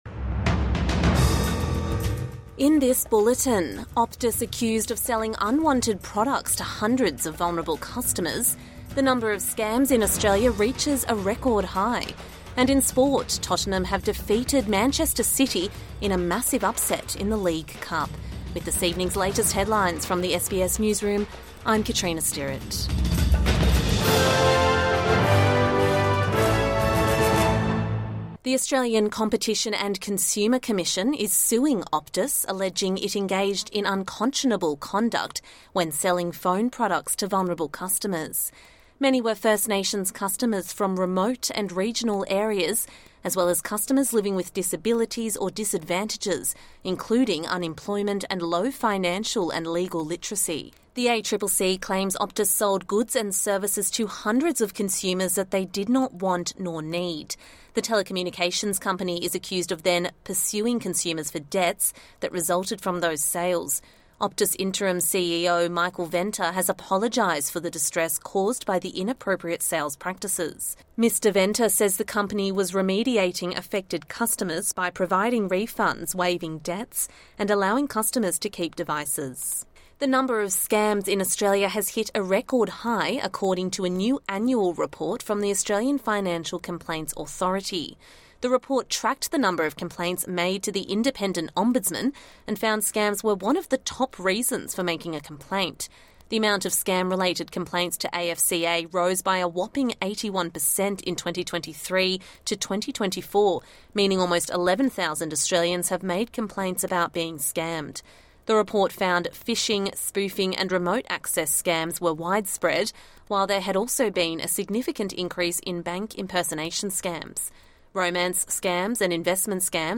Evening News Bulletin 31 October 2024